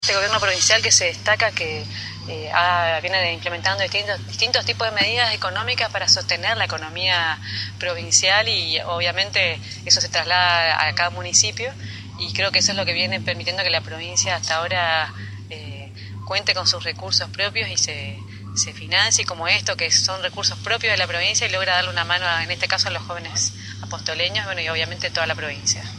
en el marco de una jornada de trabajo, evaluación de proyectos y entrevistas con Jóvenes Emprendedores de Apóstoles en el S.U.M. de la Cámara de Comercio de Apóstoles
Declaraciones